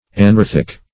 Anorthic \A*nor"thic\, a. [See Anorthite.]